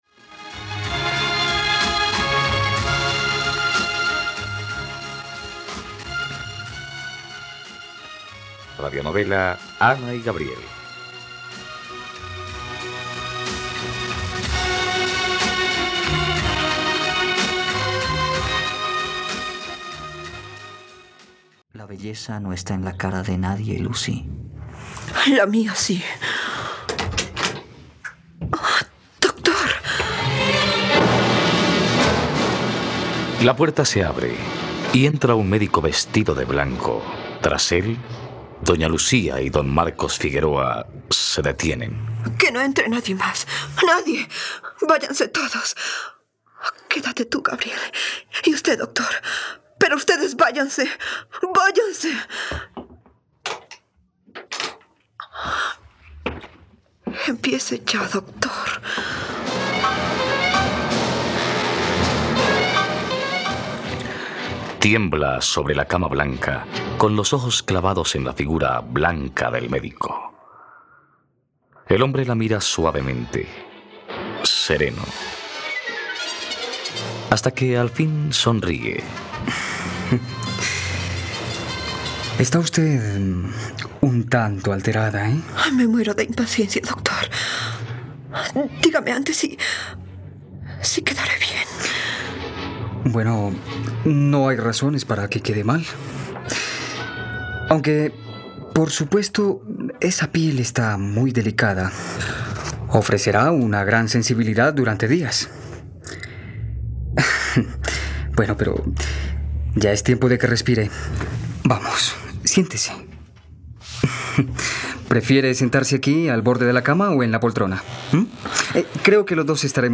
..Radionovela. Escucha ahora el capítulo 58 de la historia de amor de Ana y Gabriel en la plataforma de streaming de los colombianos: RTVCPlay.